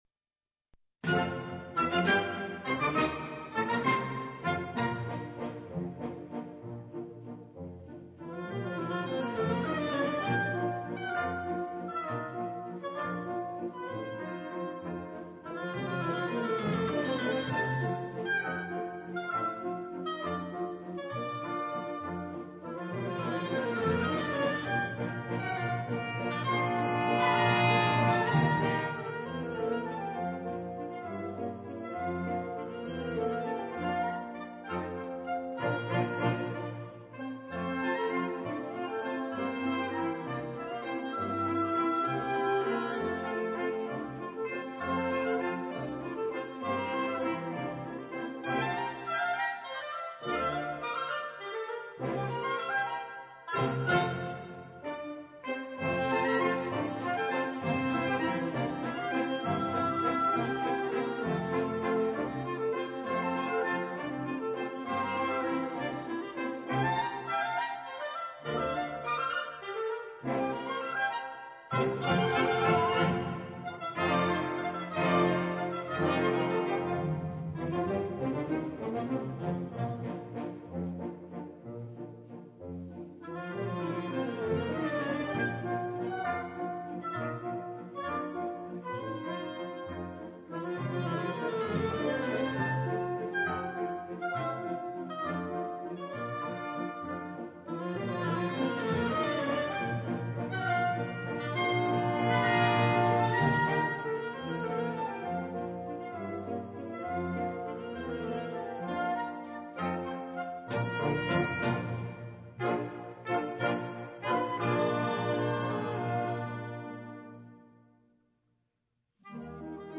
وهي على العموم موسيقى رقصة الفالسر Valzer
الموسيقى هي فعلاً للنمساوي ـ Johann Strauss مع تعديل بسيط هو ان اسمها Voices of Spring ، و هي نفسها موسيقى الفالسر ...